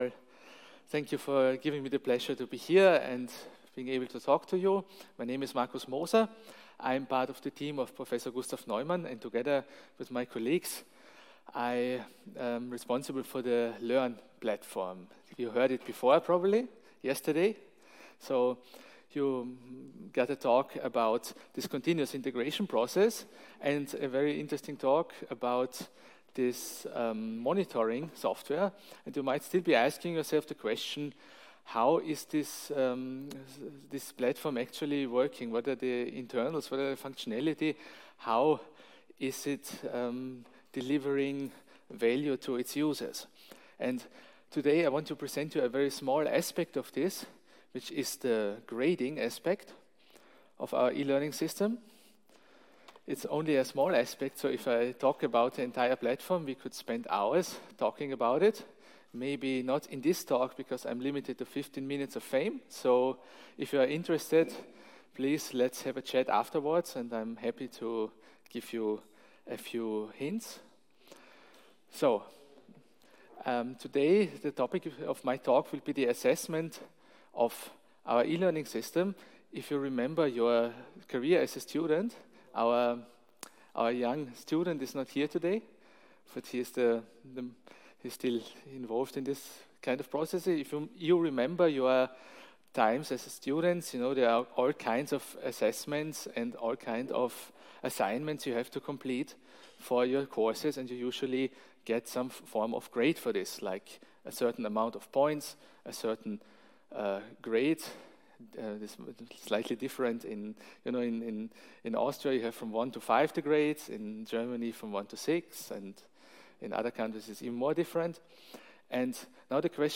European OpenACS and Tcl Conference 2022